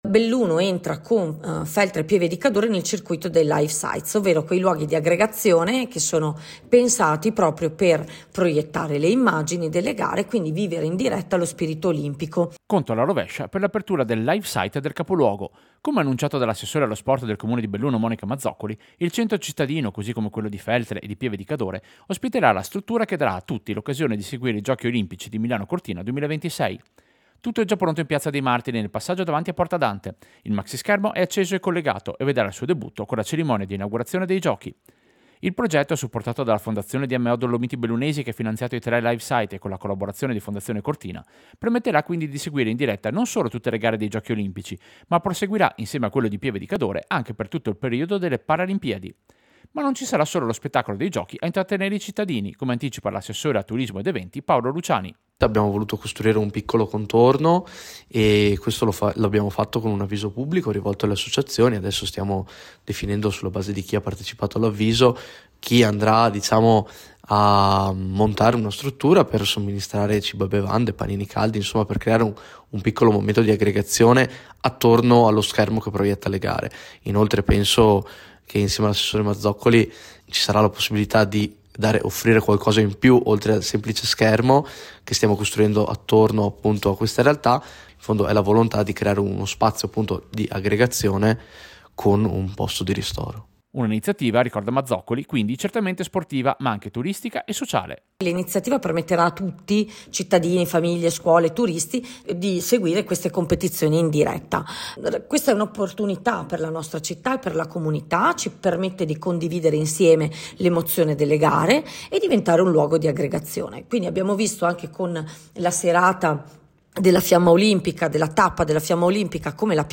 Servizio-Live-site-Olimpiadi-Belluno.mp3